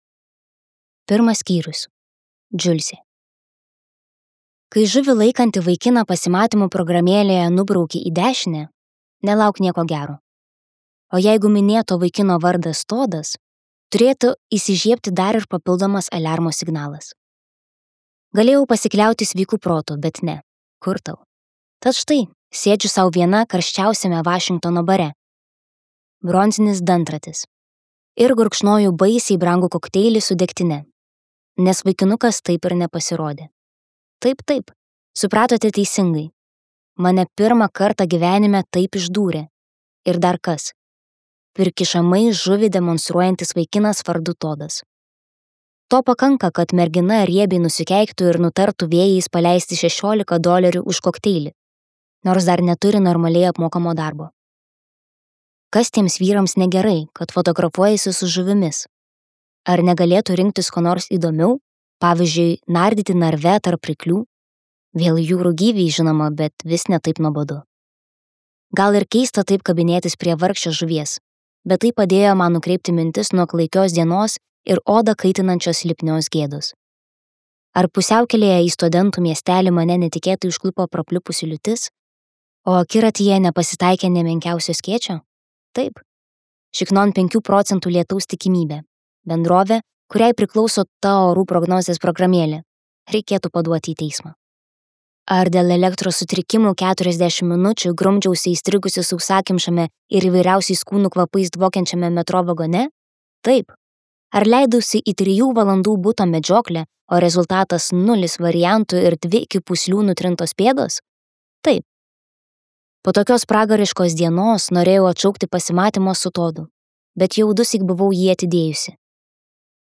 Suktas pyktis | Audioknygos | baltos lankos